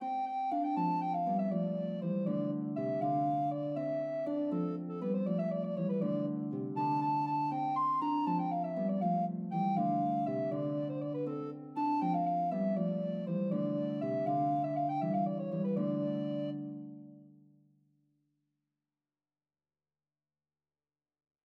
기욤 뒤파이의 "Resvelons nous, amoureux"에서는 아래 두 성부만 카논이고, 가장 높은 성부는 자유로운 선율로 되어 있다.